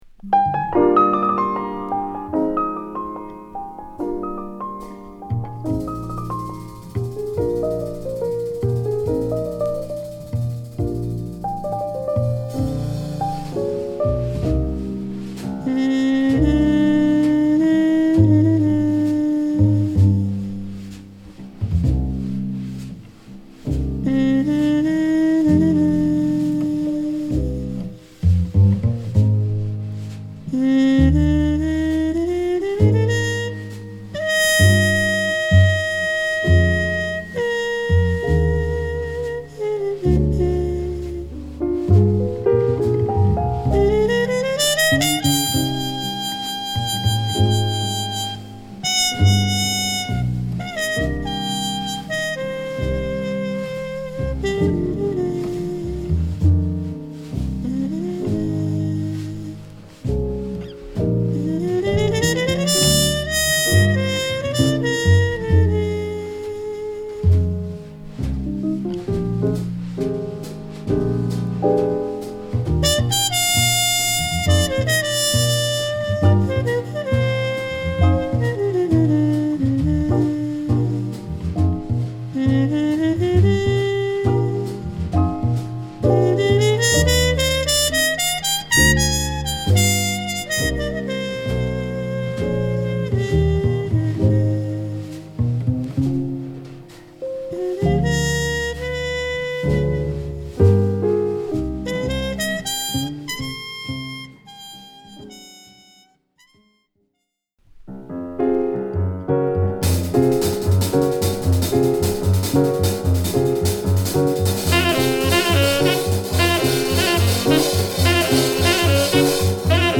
ジャズ史に欠かす事の出来無いトランペット奏者